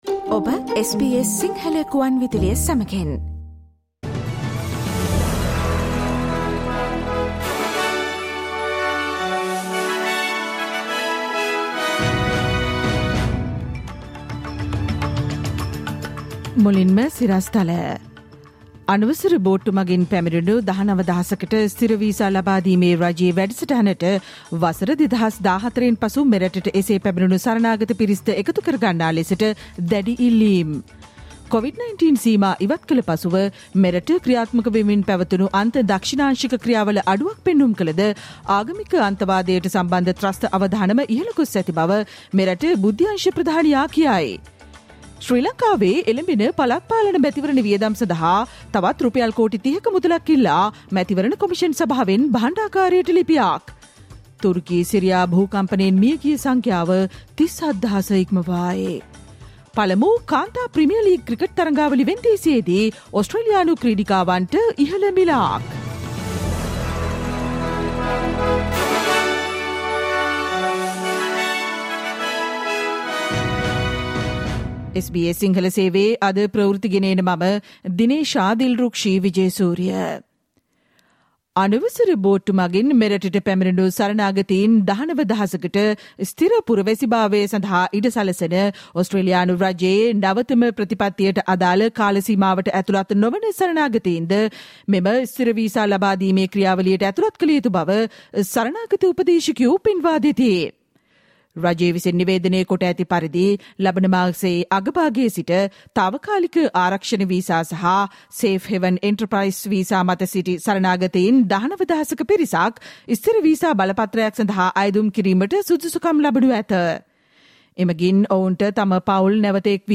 සවන්දෙන්න, අද - 2023 පෙබරවාරි 14 වන අඟහරුවාදා SBS ගුවන්විදුලියේ ප්‍රවෘත්ති ප්‍රකාශයට